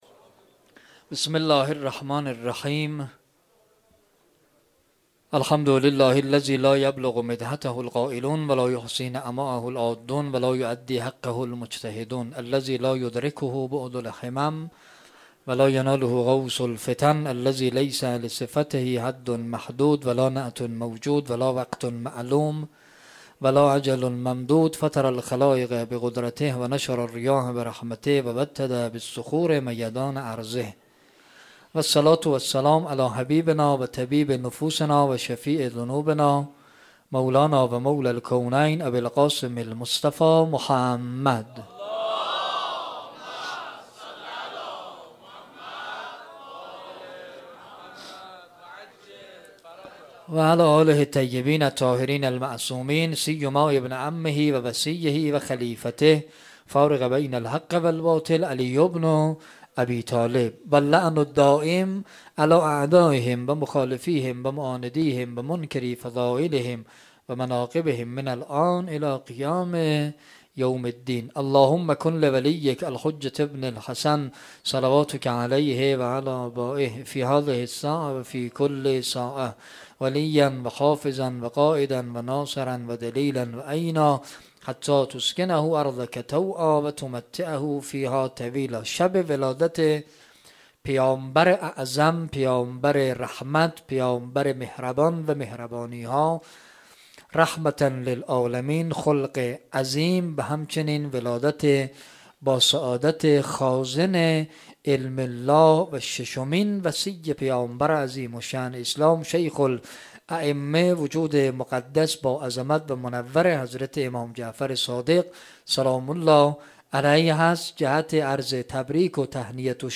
ولادت پيامبر و امام صادق (ع) 95 - فاطمیه تهران -سخنرانی